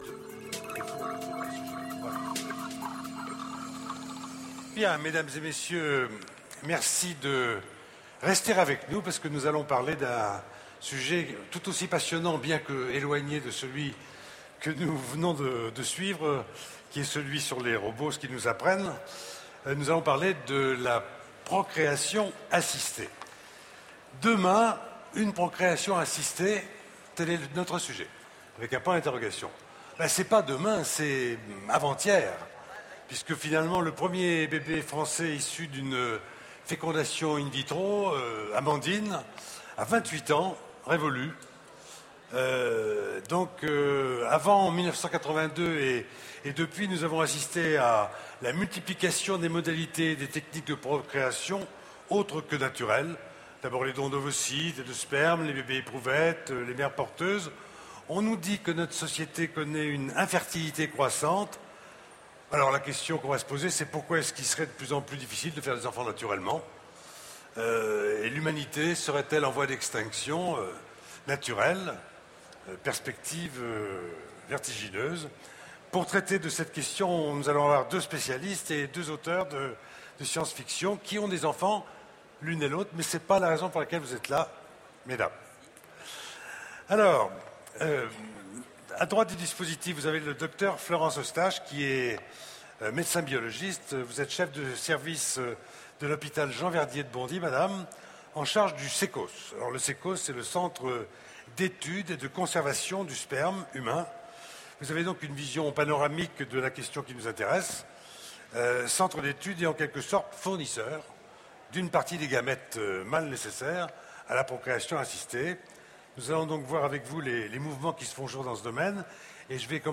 Utopiales 2010 : Conférence Inserm, Demain une procéation assistée
Voici l'enregistrement de la conférence " Demain une procéation assistée " aux Utopiales 2010.